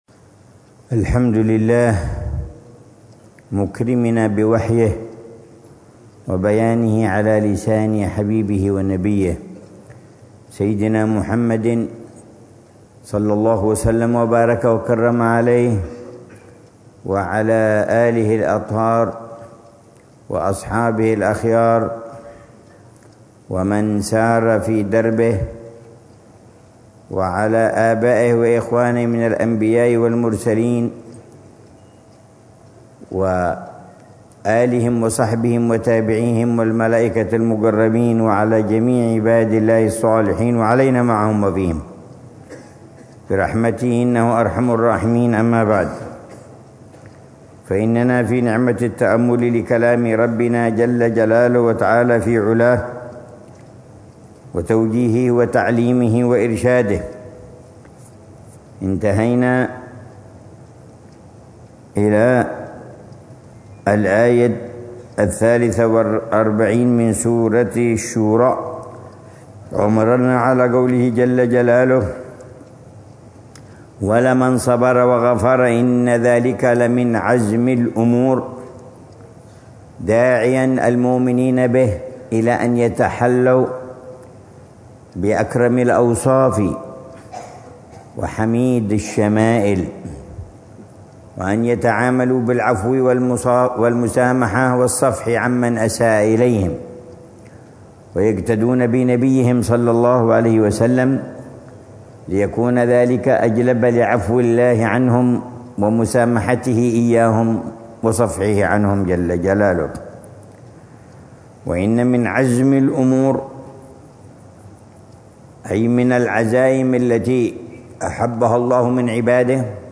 الدرس الخامس عشر من تفسير العلامة عمر بن محمد بن حفيظ للآيات الكريمة من سورة الشورى، ضمن الدروس الصباحية لشهر رمضان المبارك من عام 144